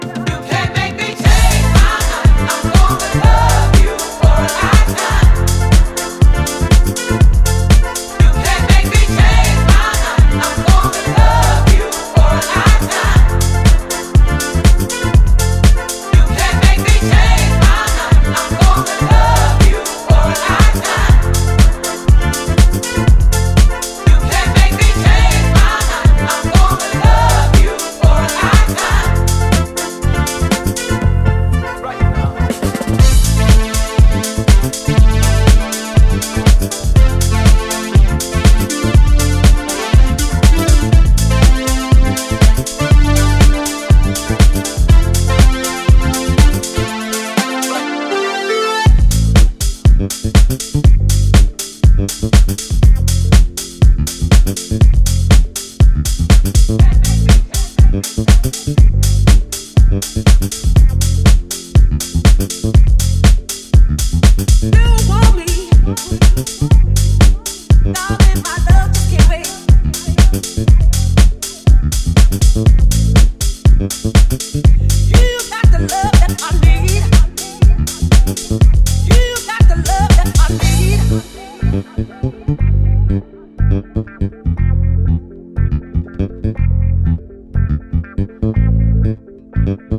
ジャンル(スタイル) DEEP HOUSE / SOULFUL HOUSE / HOUSE